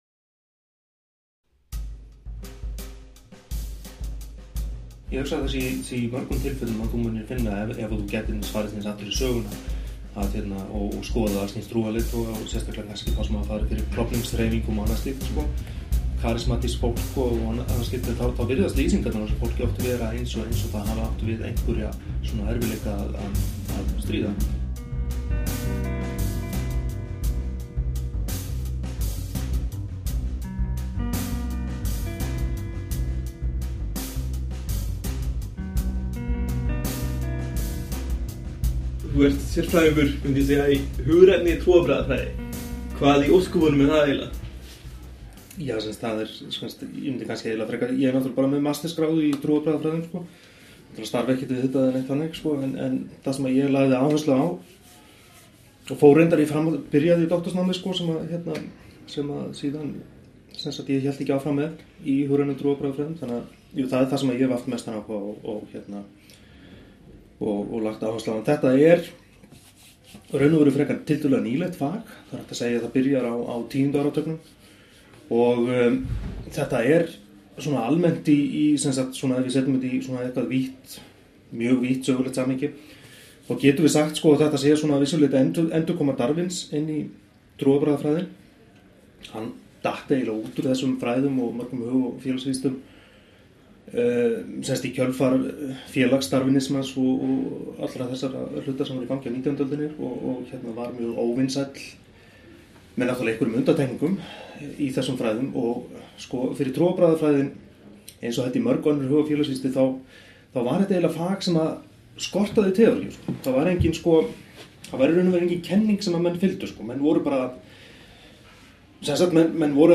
Gu�fr��i og tr�arbrag�afr��i - vi�tal